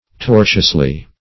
tortiously - definition of tortiously - synonyms, pronunciation, spelling from Free Dictionary Search Result for " tortiously" : The Collaborative International Dictionary of English v.0.48: Tortiously \Tor"tious*ly\, adv.